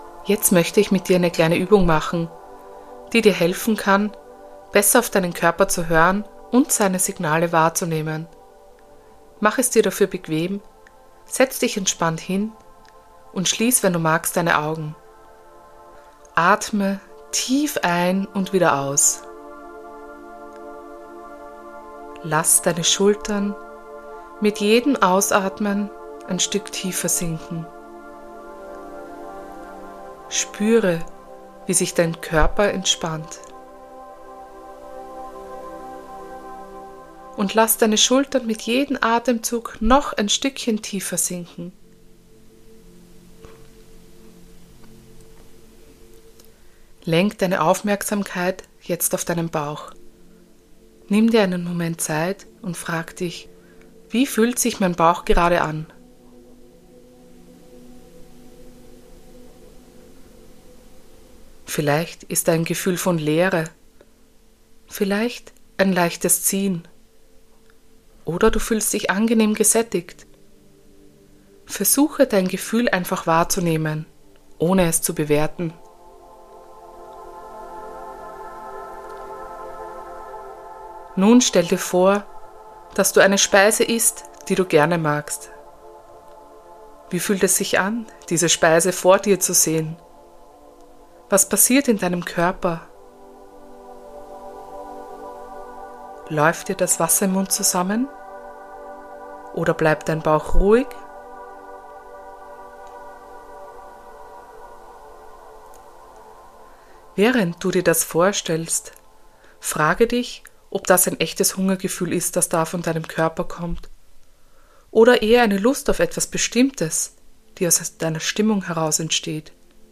In dieser Episode erwartet dich eine geführte Übung, die dir hilft,